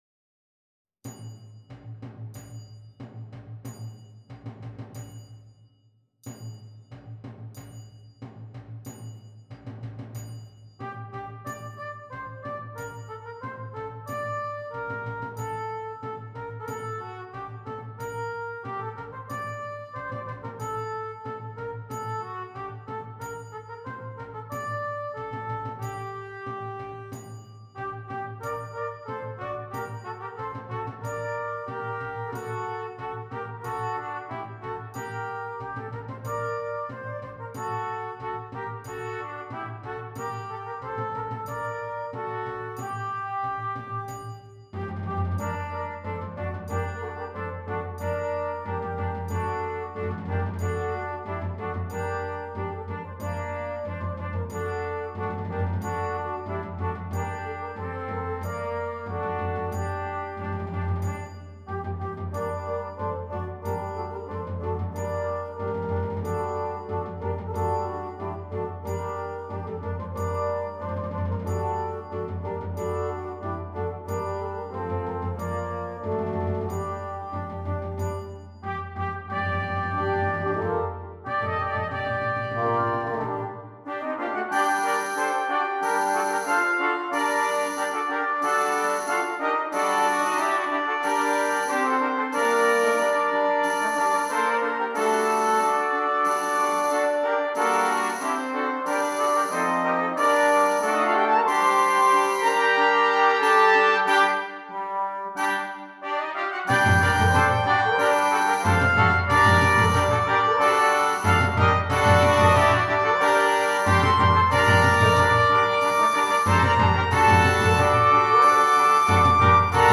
6 Trumpets
Traditional French Carol